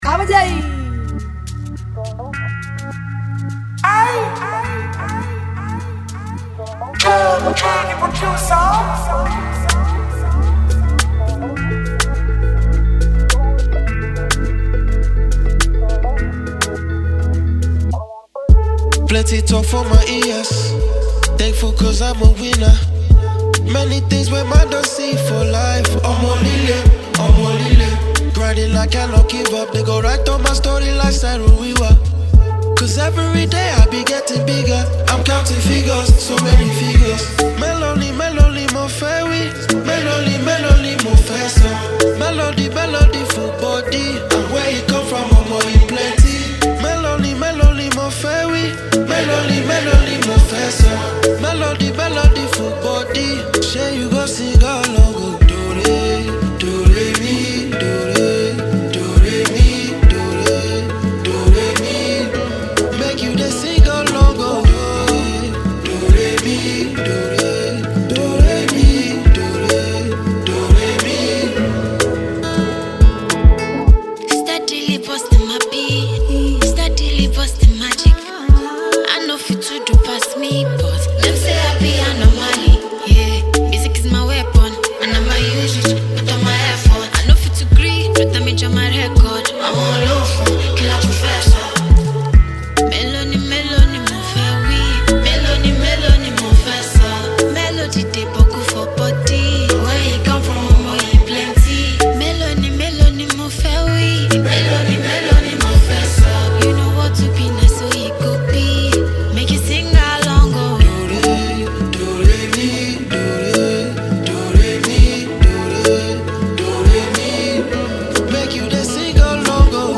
featuring the soulful vocals
sets a vibrant, energetic tone
smooth, emotive voice adds a layer of tenderness and warmth.